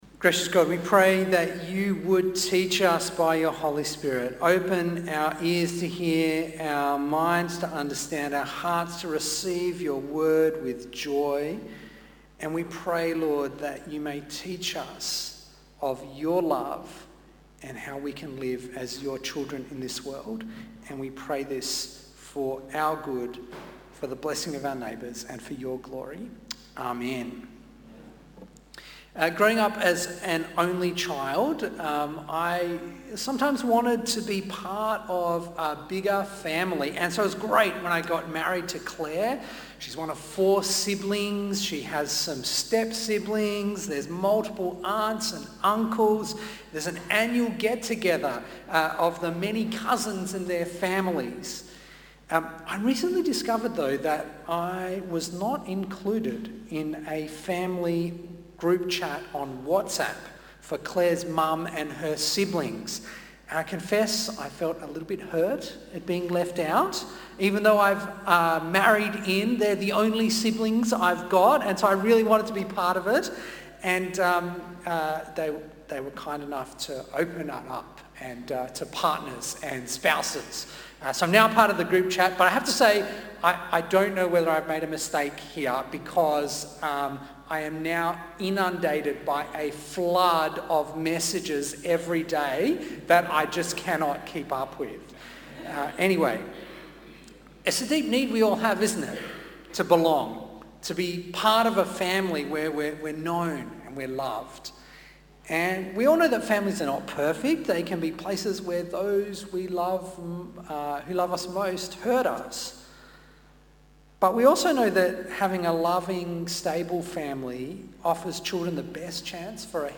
Questions of "Who am I?" and "Where do I belong?" are key to our humanity. This sermon explores how in Baptism and the Church God gives us an answer.